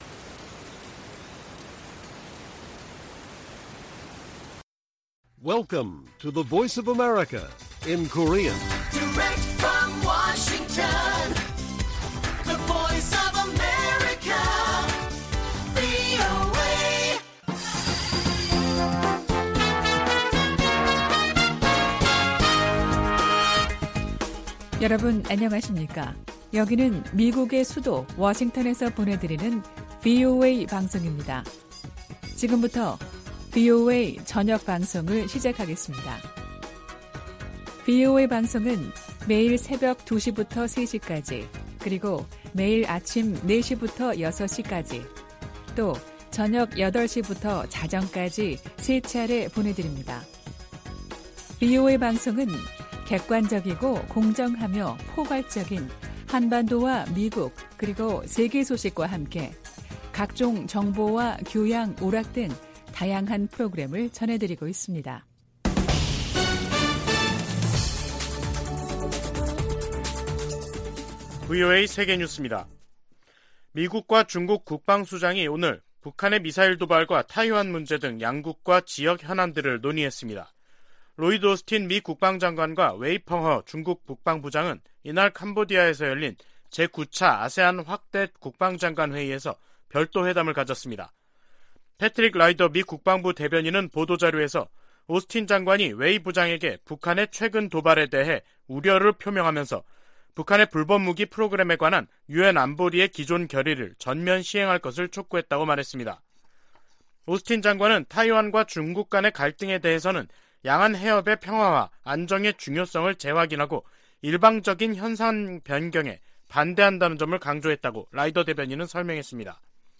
VOA 한국어 간판 뉴스 프로그램 '뉴스 투데이', 2022년 11월 22일 1부 방송입니다. 유엔 안보리가 북한의 대륙간탄도미사일(ICBM) 발사에 대응한 공개회의를 개최하고 북한을 규탄했습니다. 북한의 화성 17형 시험발사에 대응해 21일 소집된 유엔 안보리에서 중국과 러시아는 북한의 도발이 미국 때문이라는 주장을 반복했습니다.